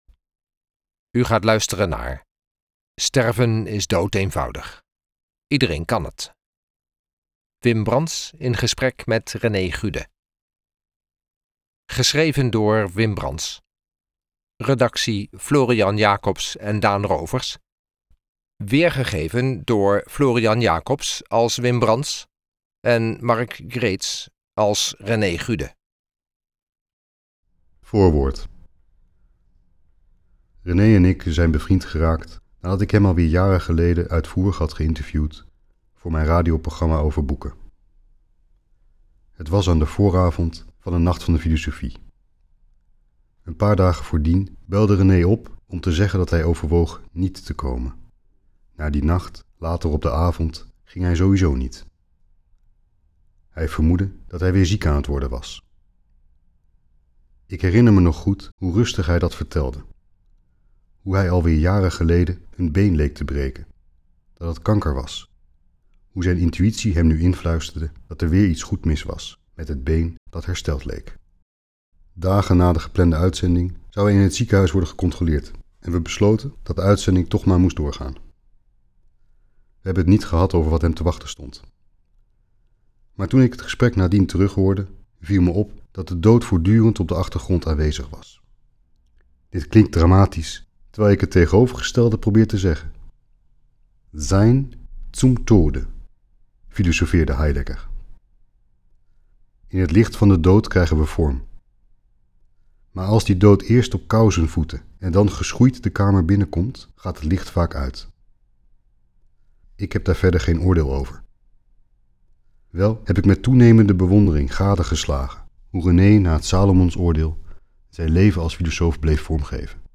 luisterboek
Wim Brands in gesprek met René Gude